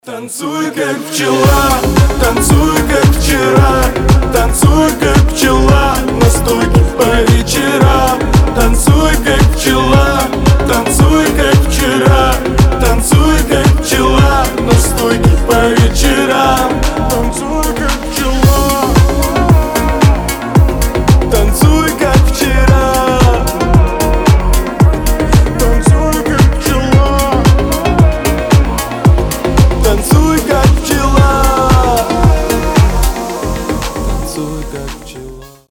• Качество: 320, Stereo
поп
мужской вокал
дуэт